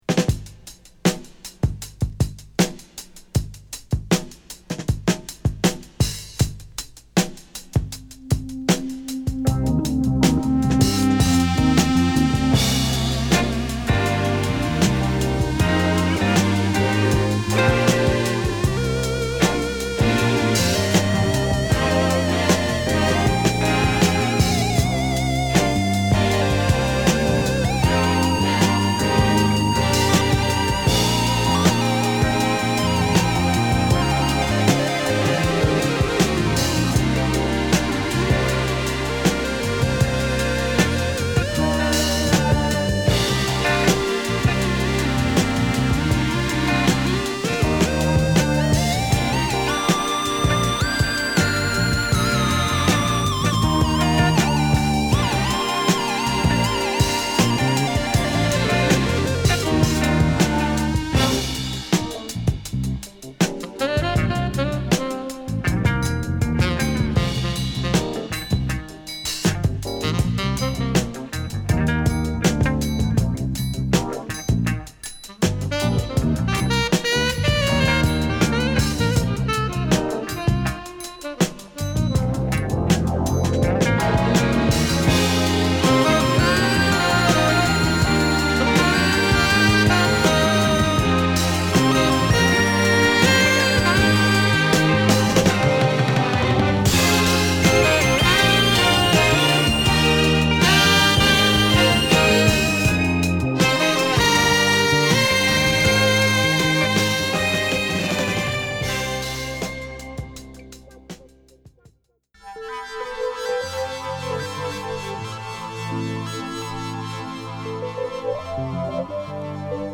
マルチな才能を発揮した鍵盤奏者